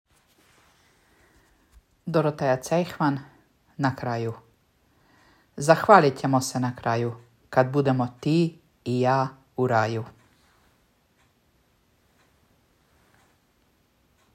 čita